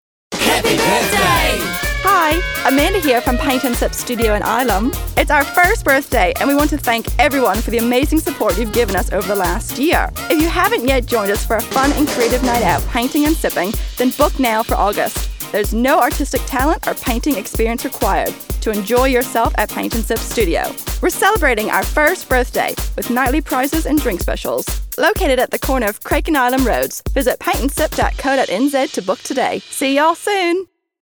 1st Birthday Radio Ads